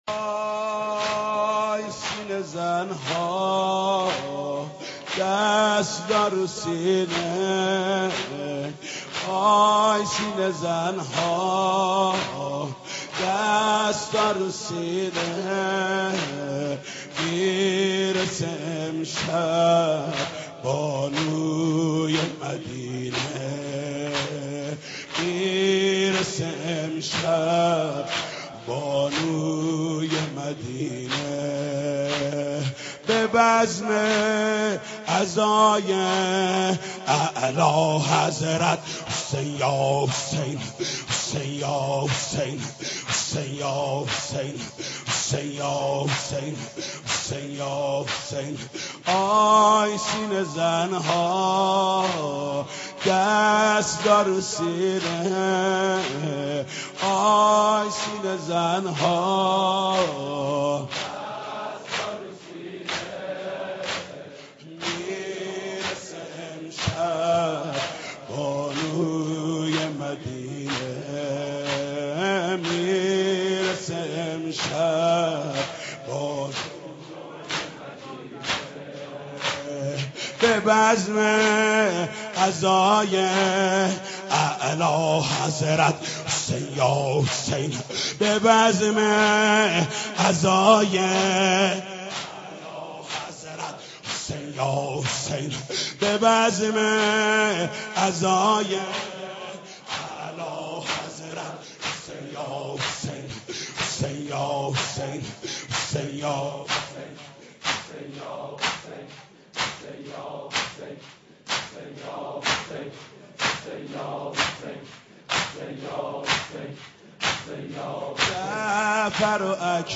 اشعار ورودیه کربلا به همراه سبک با صدای حاج محمود کریمی/زمینه -( آی سینه زن ها دستا رو سینه )